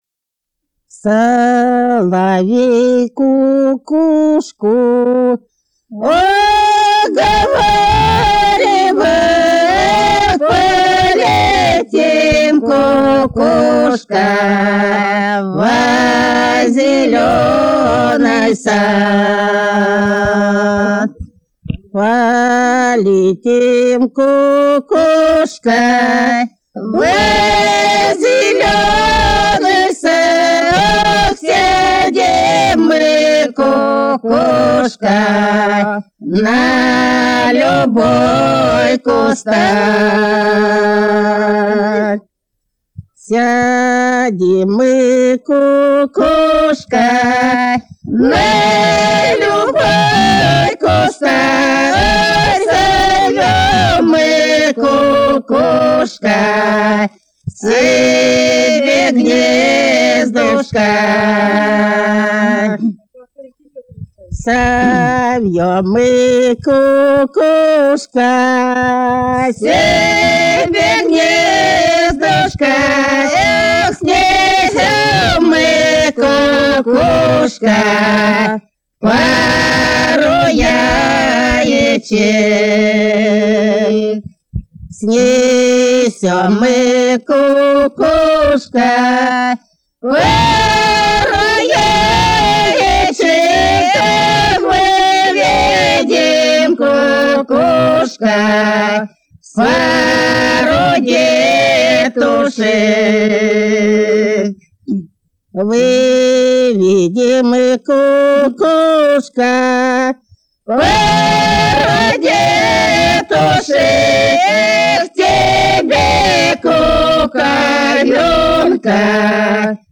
14_-_14_Соловей_кукушку_уговаривал,_протяжная.mp3